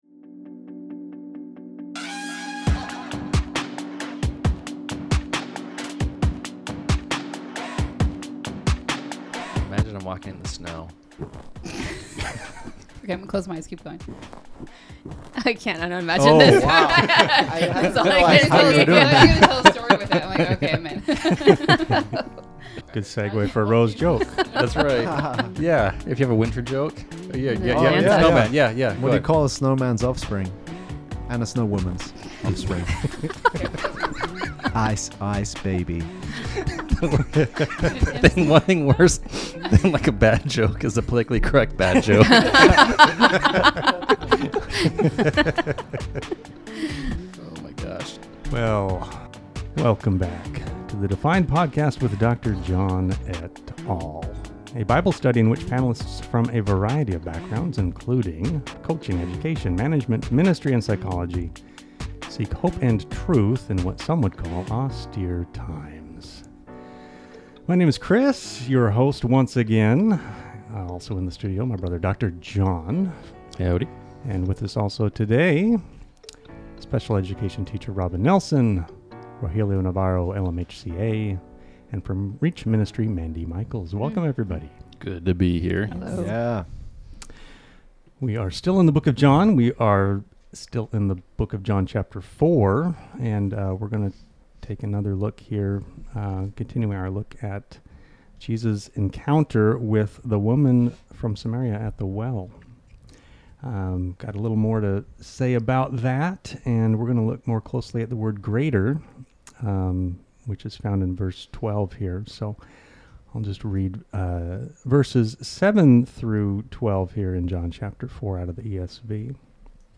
Panelists discuss what it means to be great, how Jesus used greatness to describe John the Baptist, and how Jesus said that the one who is least in the kingdom of God is greater than John.